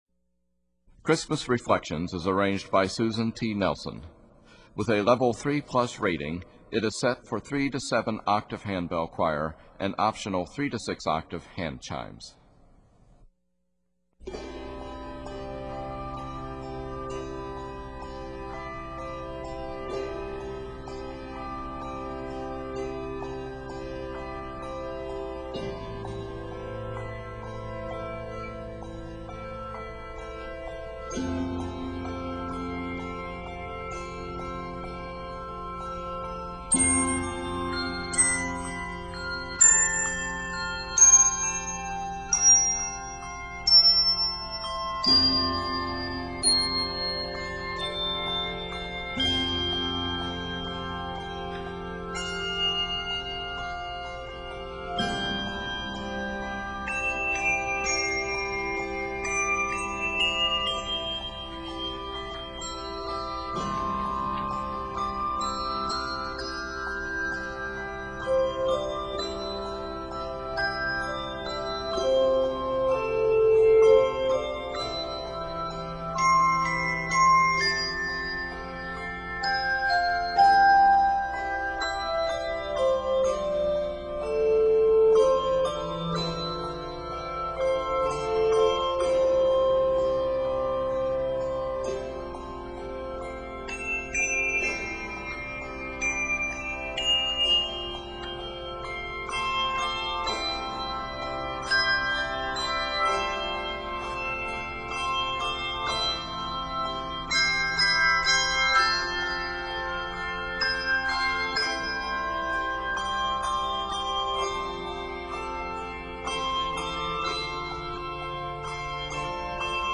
Octaves: 3-7